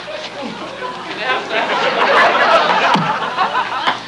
Group Laughter Intro Sound Effect
Download a high-quality group laughter intro sound effect.
group-laughter-intro.mp3